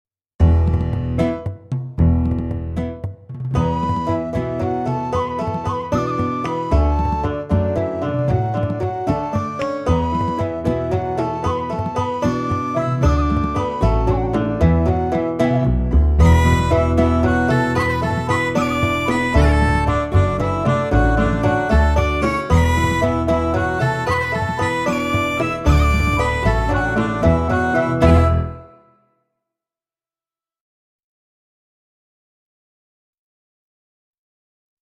VS Peeler's Jig (backing track)